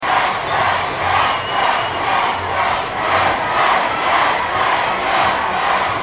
دانلود صدای شعار دادن در استادیوم از ساعد نیوز با لینک مستقیم و کیفیت بالا
جلوه های صوتی